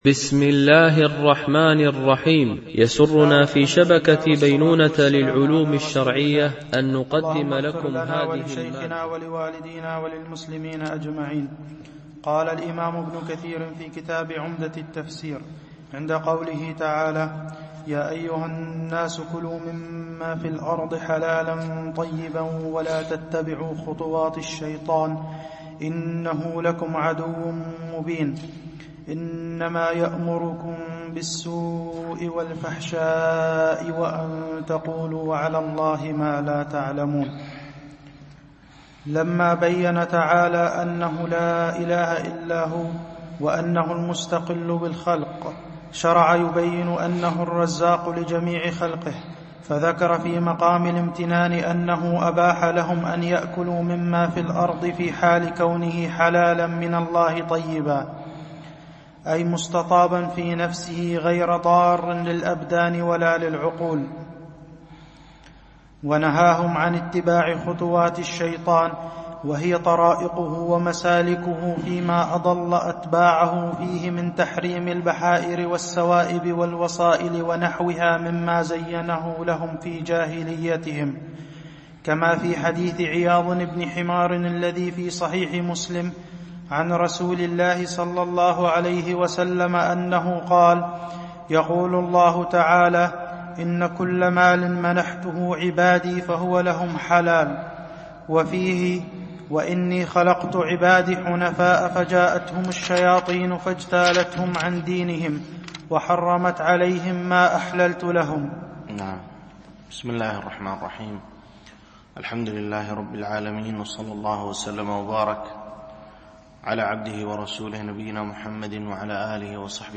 شرح مختصر تفسير ابن كثير (عمدة التفسير) ـ الدرس 24 (سورة البقرة، الآية 168 - 176)